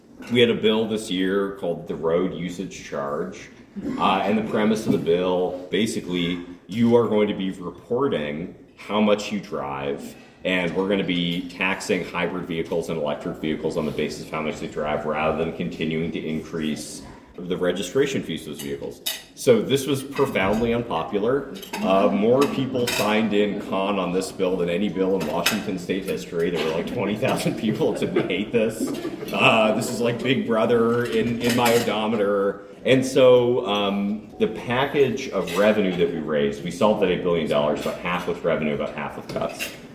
Rep. Bernbaum spent the bulk of the hour taking questions. Since he is on the House Transportation Committee, we asked him what folks in the 24th District can expect from this year’s final transportation budget.